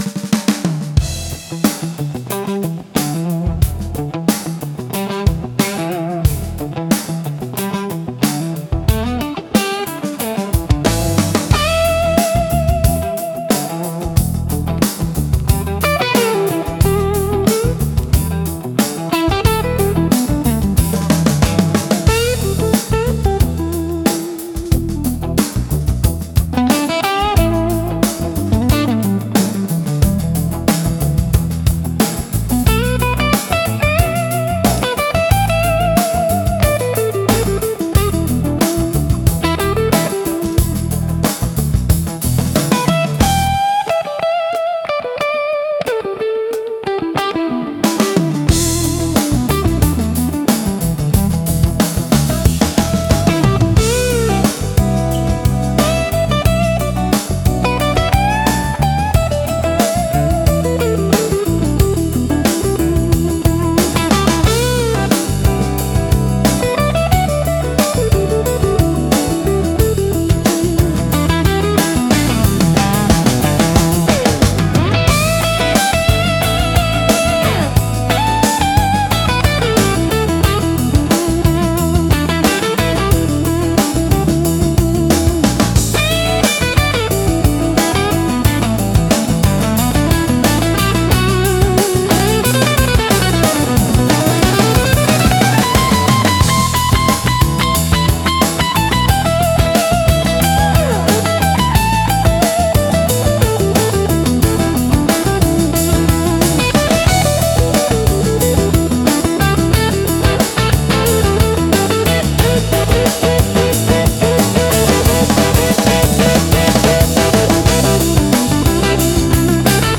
人生の苦悩や喜び、希望を表現する独特の温かみと哀愁があり、聴き手の心に響く情感豊かなシーン演出に向いています。